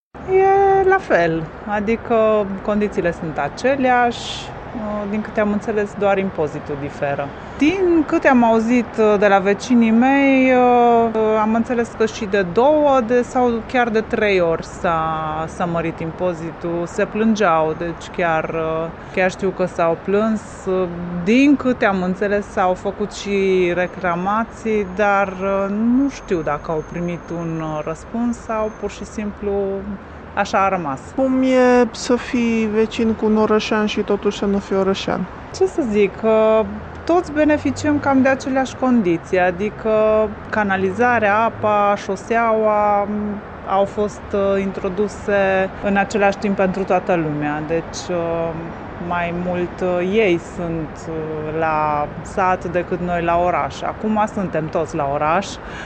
Locuitorii nu par deranjaţi de situaţie, ba chiar se amuză şi se invită unii pe alţii la oraş sau la sat: